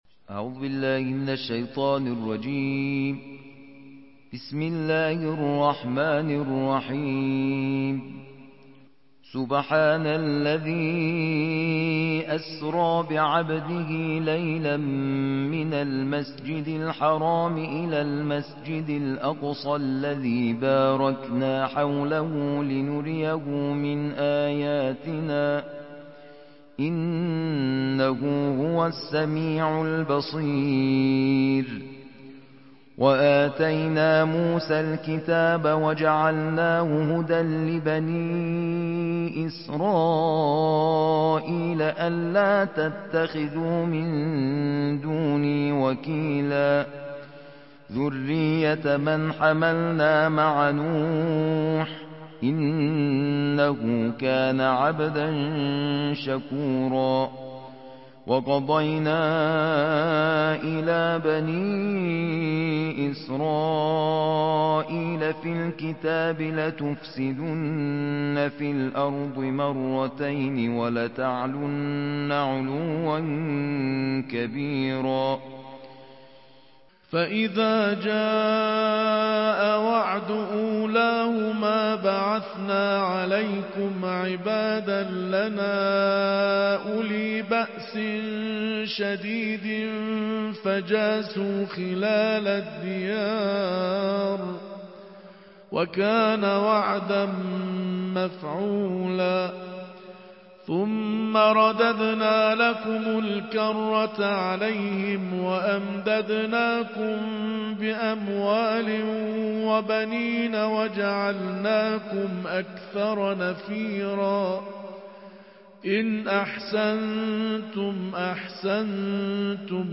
نړیوال قارِيان ،د قرآن کریم د پنځلسمې(۱۵) سپارې یا جزوې د ترتیل قرائت